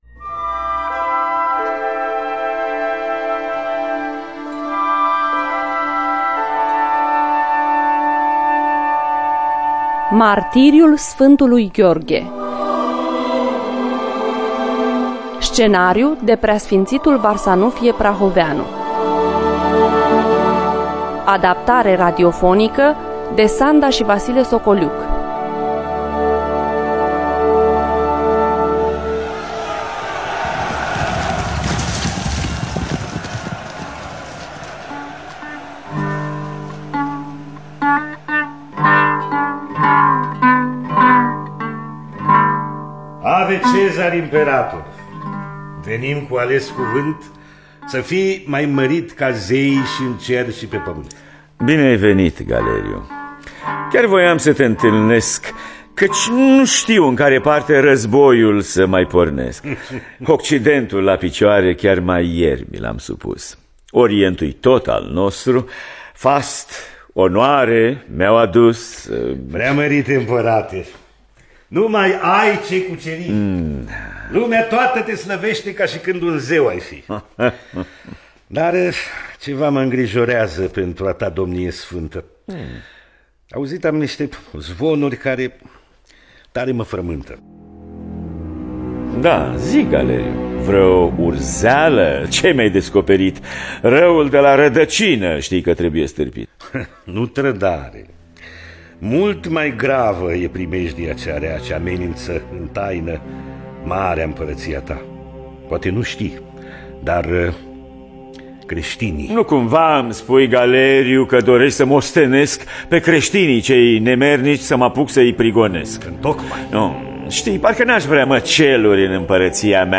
Varsanufie Prahoveanul – Martiriul Sfantul Gheorghe (2006) – Teatru Radiofonic Online